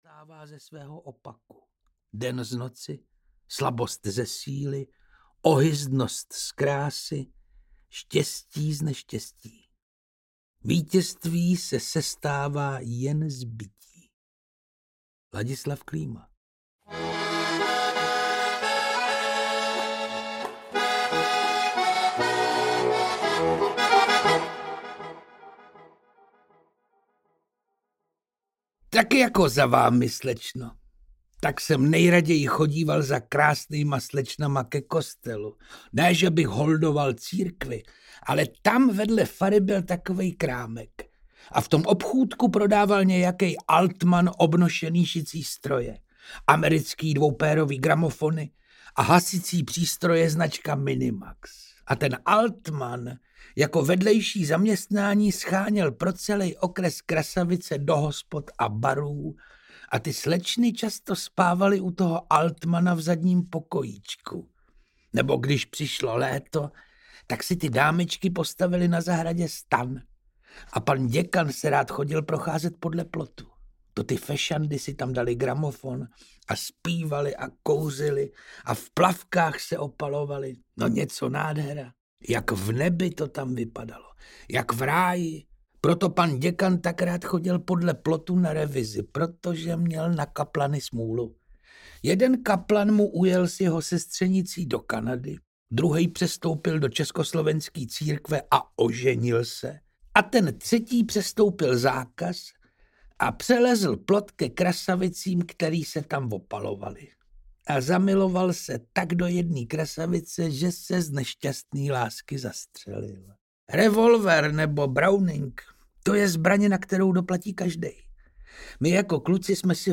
Audio knihaTaneční hodiny pro starší a pokročilé
Ukázka z knihy
• InterpretPetr Čtvrtníček